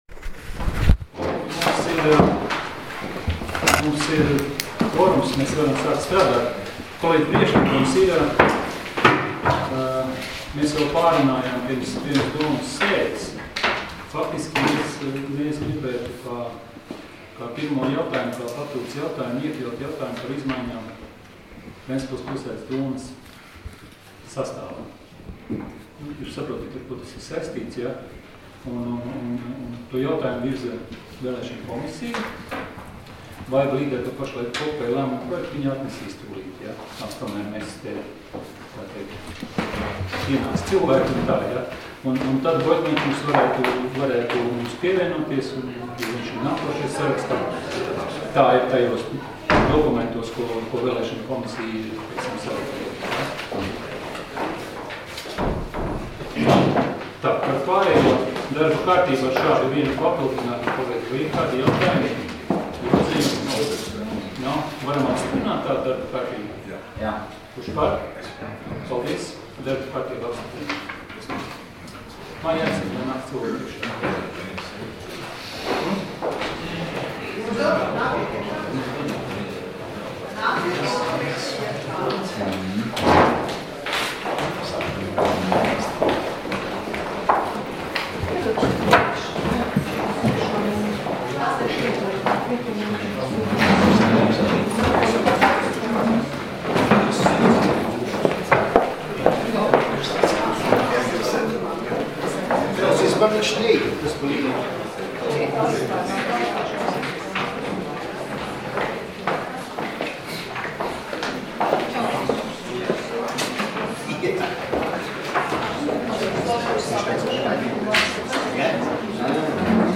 Domes sēdes 25.04.2017. audioieraksts